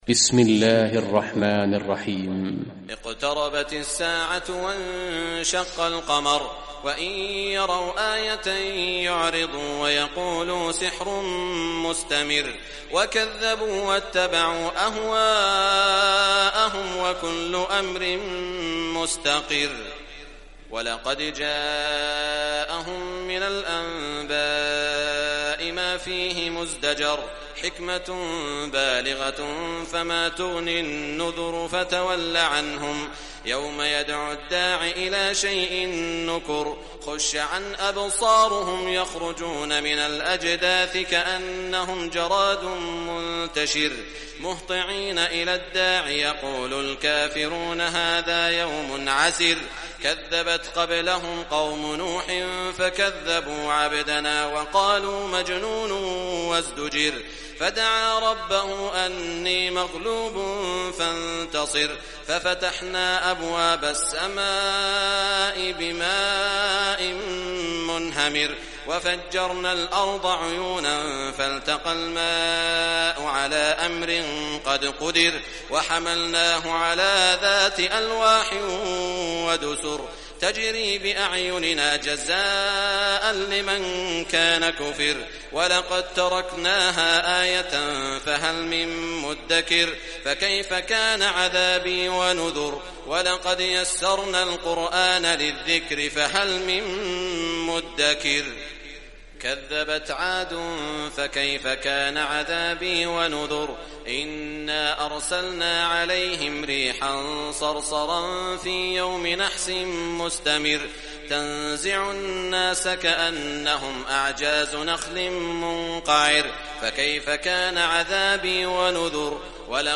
Surah Qamar Recitation by Sheikh Shuraim
Surah Qamar, listen or play online mp3 tilawat / recitation in Arabic in the beautiful voice of Sheikh Saud al Shuraim.